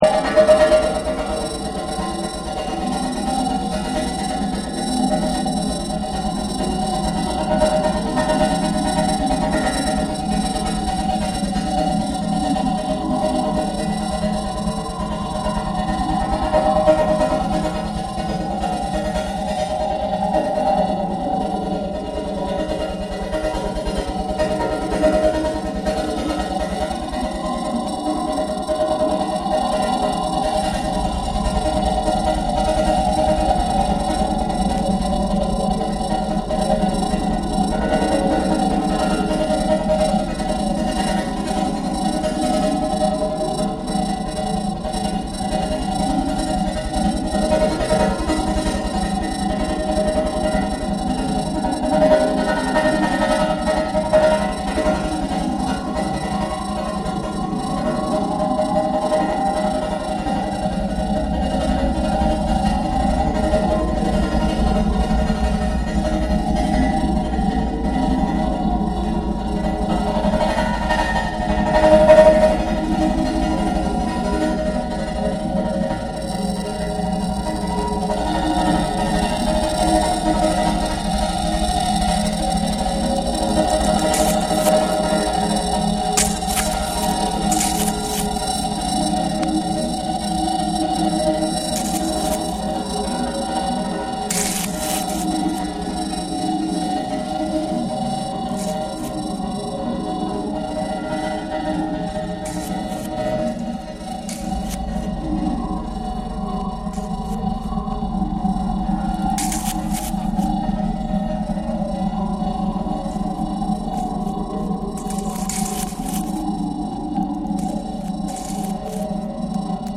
BREAKBEATS
カリビアン・テイストなトロピカルナンバーから、もちろんレゲエ〜ダブまで楽しめる隠れた（？）好作！